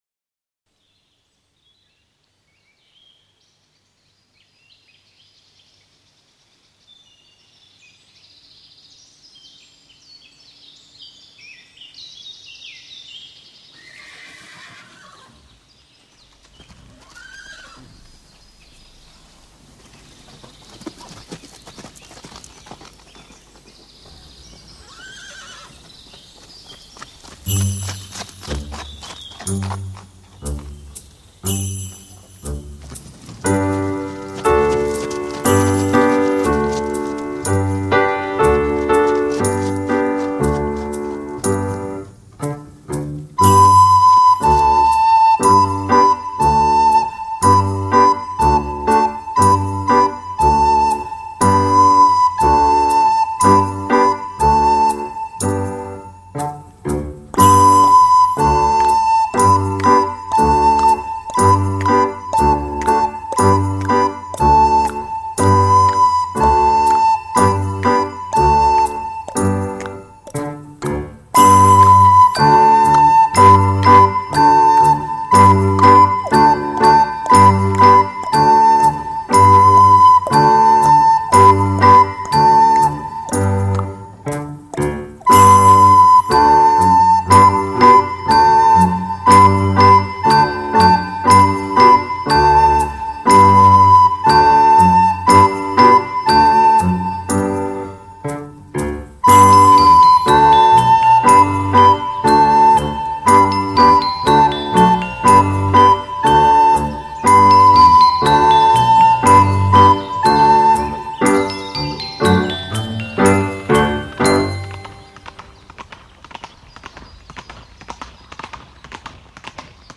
Audicions de flauta